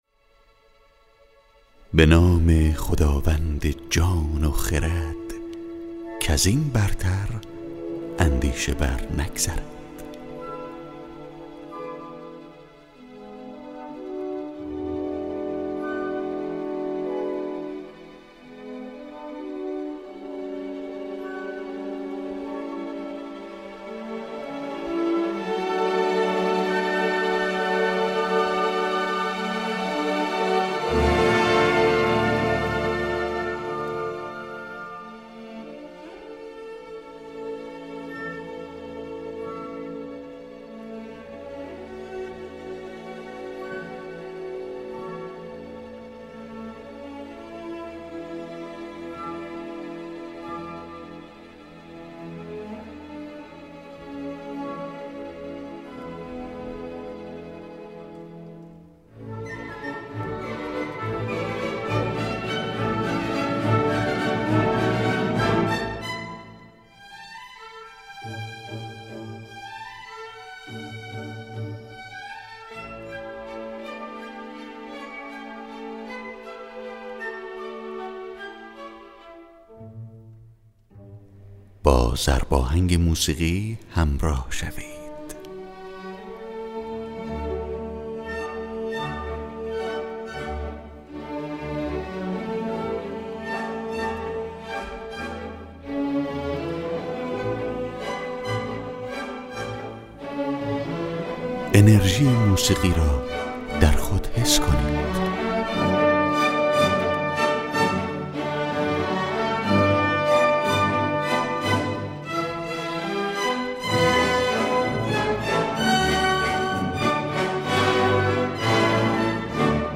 نیرو بخش: